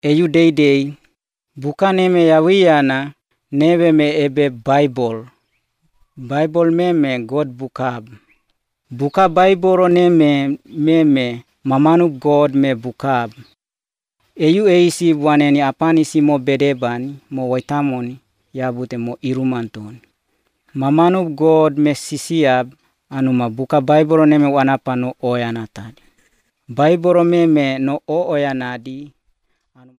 These are recorded by mother-tongue speakers